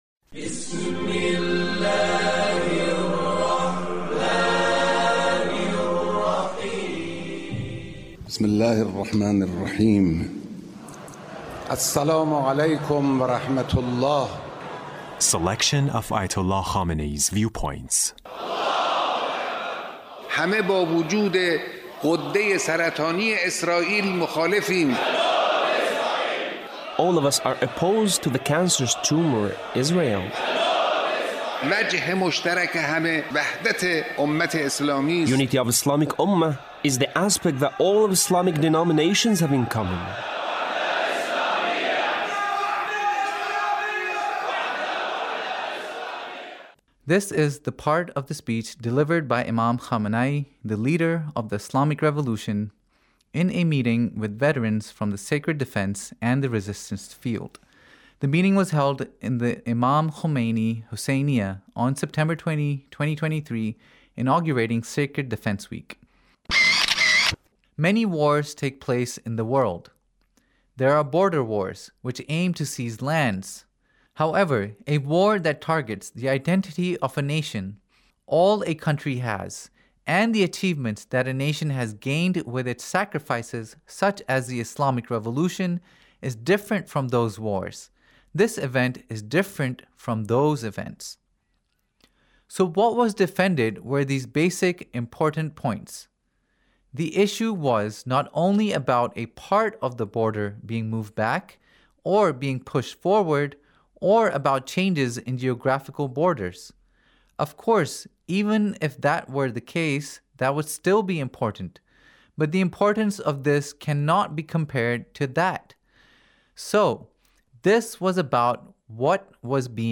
Leader's Speech about Sacred defense